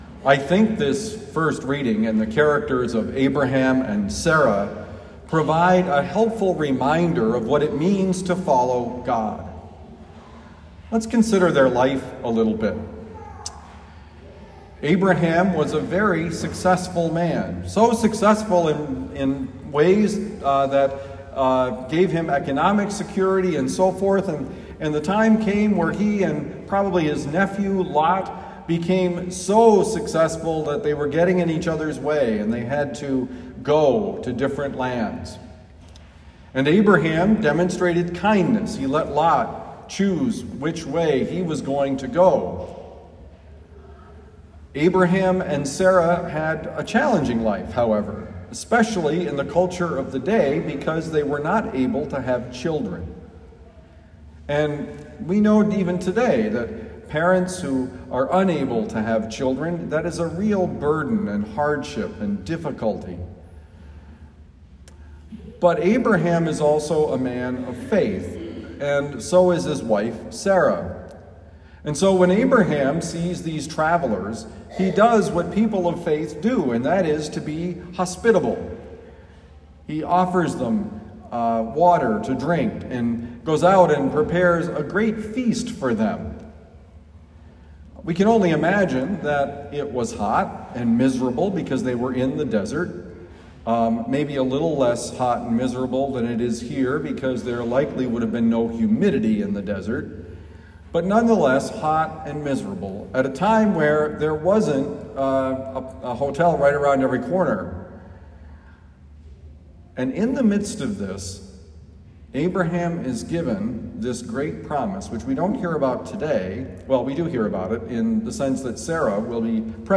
Homily given at Our Lady of Lourdes, University City, Missouri.